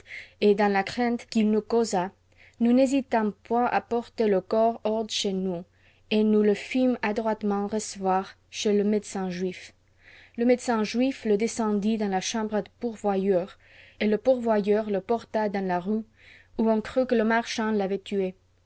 female_103.wav